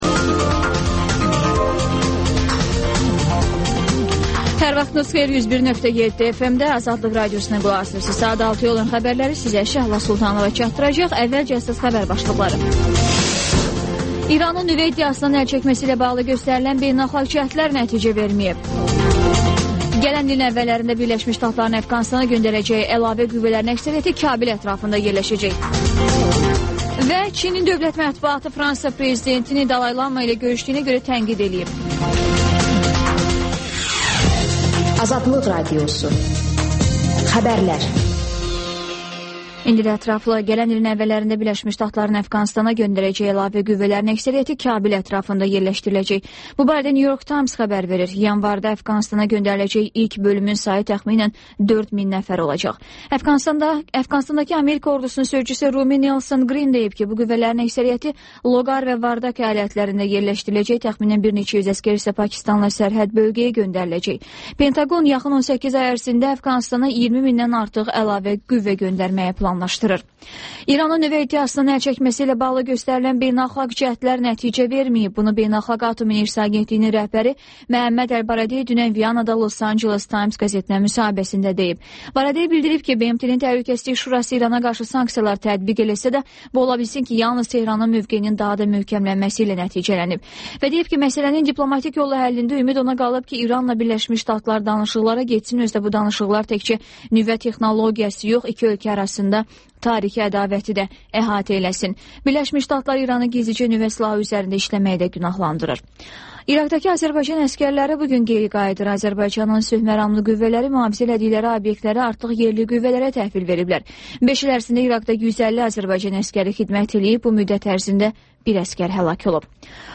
Xəbərlər, QAYNAR XƏTT: Dinləyici şikayətləri əsasında hazırlanmış veriliş, sonda 14-24